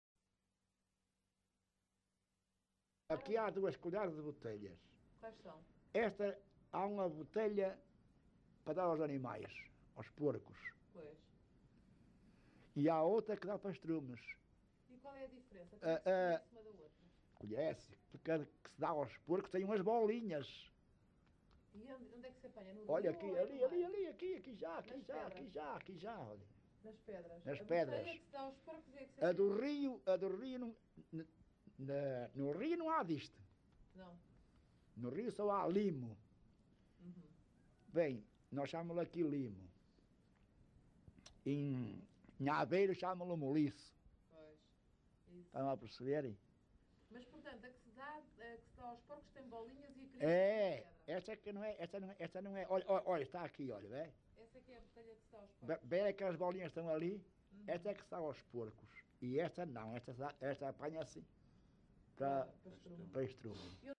LocalidadeVila Praia de Âncora (Caminha, Viana do Castelo)